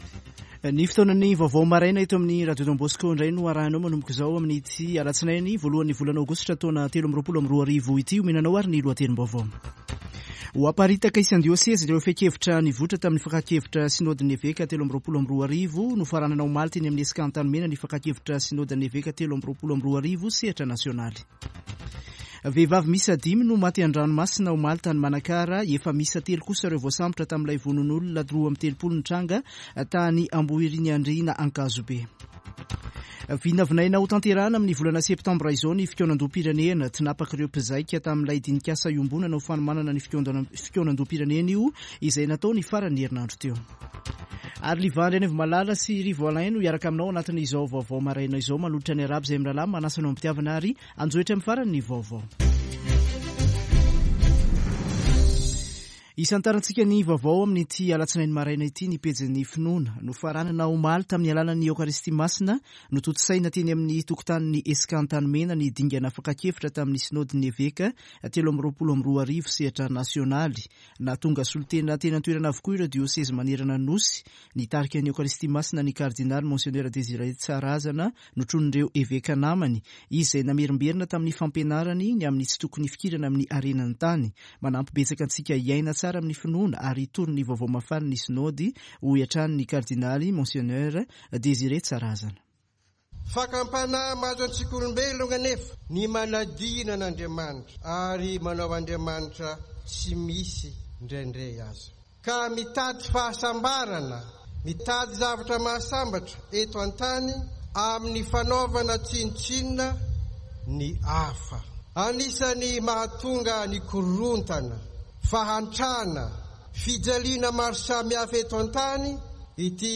[Vaovao maraina] Alatsinainy 01 aogositra 2022